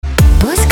• Качество: 320, Stereo
красивые
женский вокал
dance
спокойные
vocal